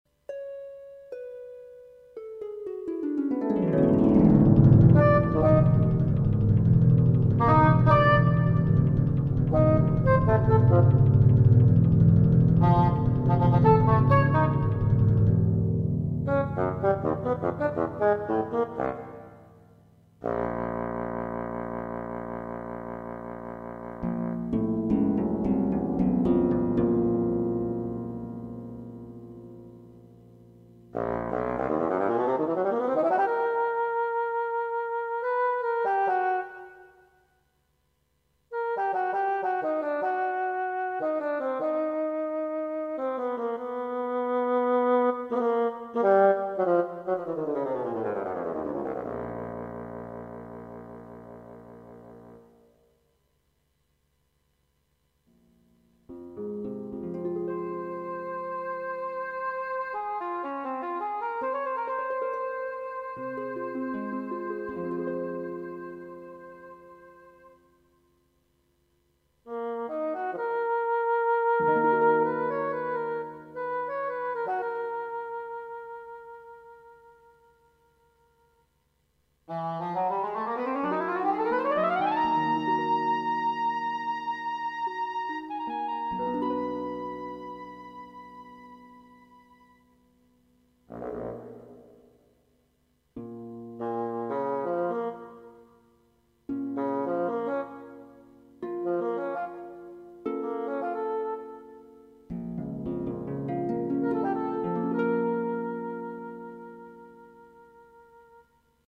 The 3rd movement is a dialogue between the two soloists accompanied by harp, which also joins in the conversation. In some ways this movement resembles an accompanied cadenza, but it’s not just for technical display, and there is a miniature psychological drama in the central section where the instruments (and the tonality) become disoriented, the bassoon despairs, the cor anglais encourages, and the bassoon gradually, falteringly regains its confidence:
What you hear here has been carefully crafted from sampled and synthesized sounds to be as realistic as possible, but synthesized music is never as good as the real thing.
Kin-III-mini-drama.mp3